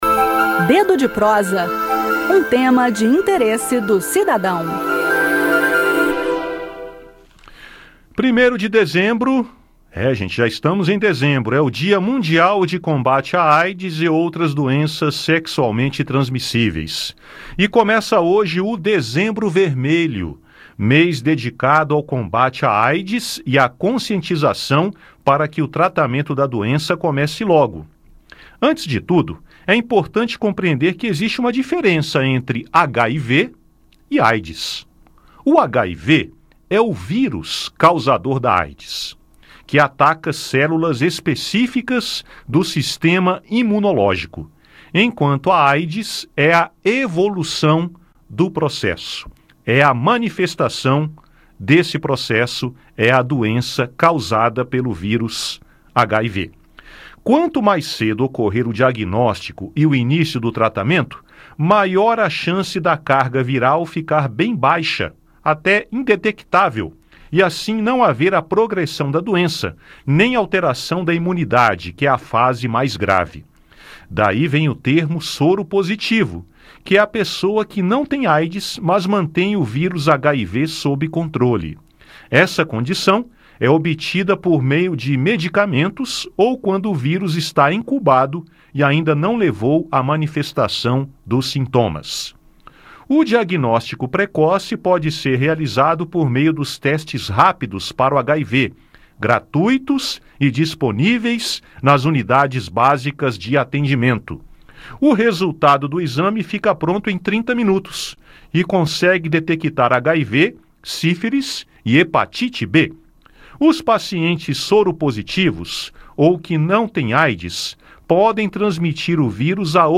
bate um papo sobre o assunto no “Dedo de Prosa” desta terça-feira